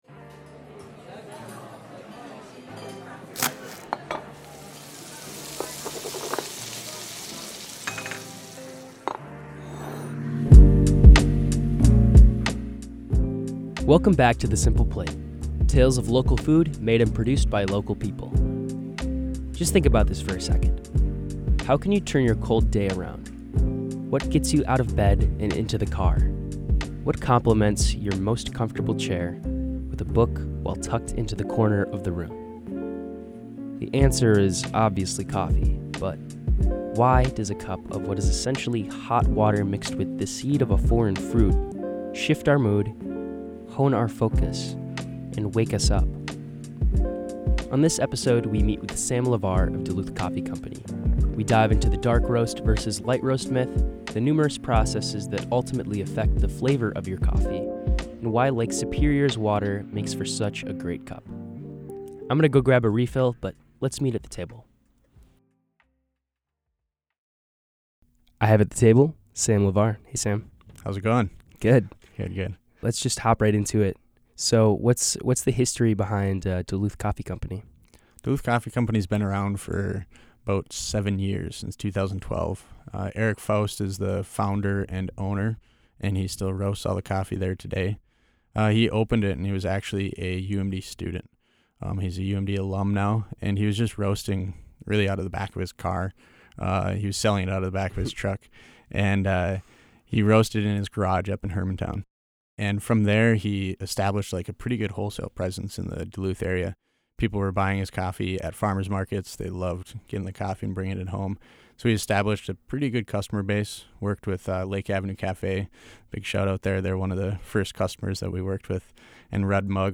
You'll hear conversations with local chefs and local food growers. We'll discuss food culture, visit area food events, and even share a few recipes.